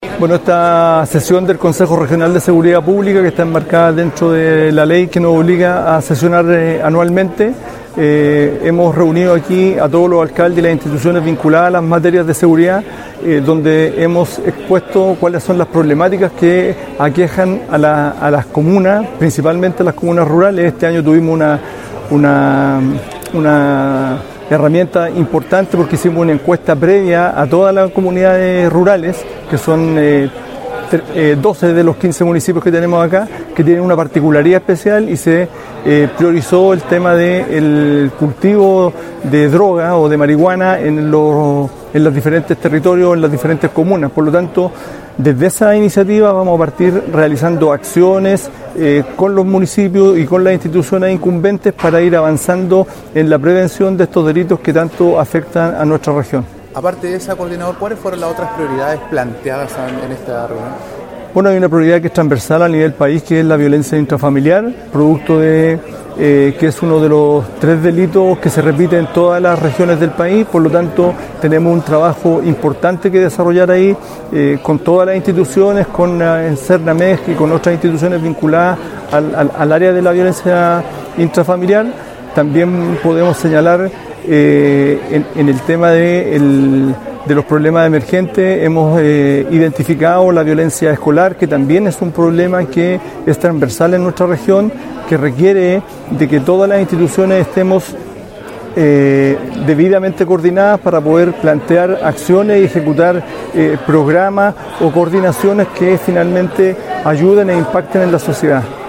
Asimismo, Abel Lizama, Coordinador Regional de Seguridad Pública, indicó que
CONSEJO-SEGURIDAD-Abel-Lizama-Coordinador-Seguridad-Publica.mp3